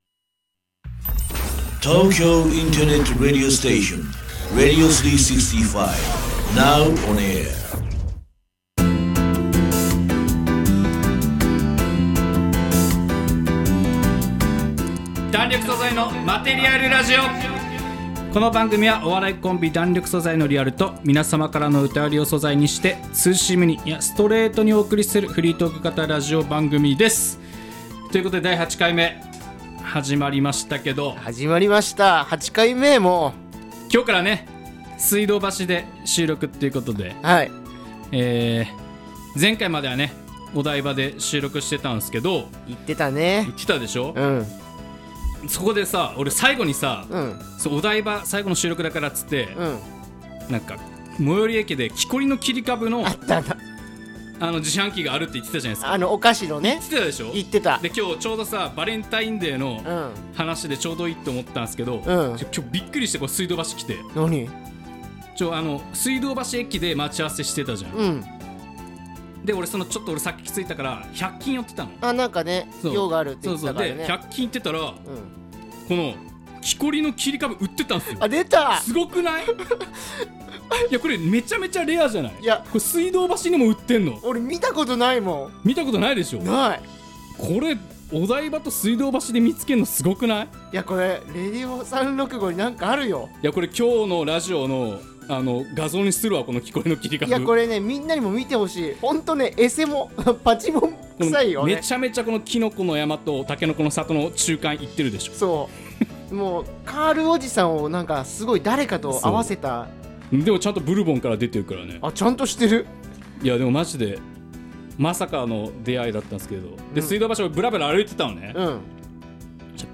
今回は収録場所が水道橋に移動して最初のラジオ収録！